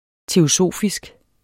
Udtale [ teoˈsoˀfisg ]